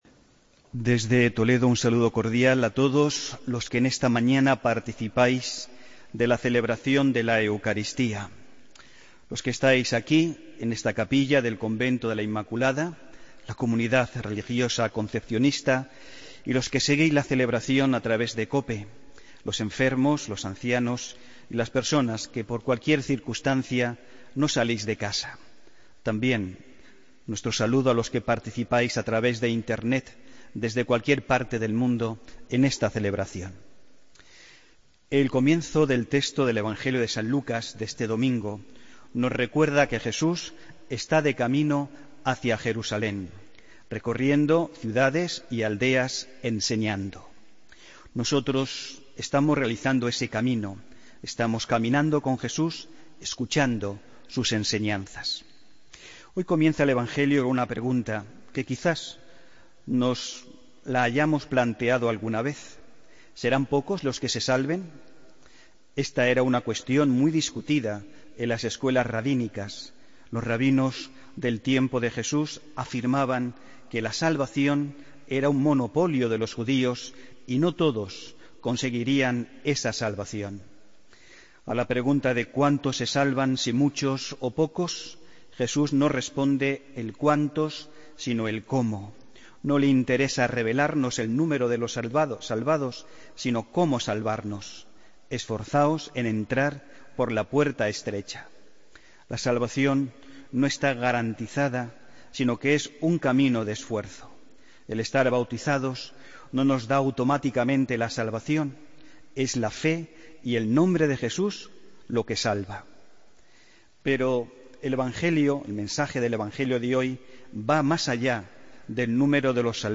Homilía del domingo 21 de agosto de 2016